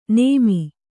♪ nēmi